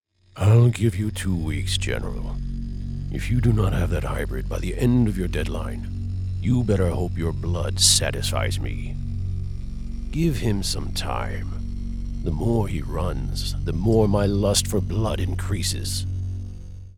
Deep and sinister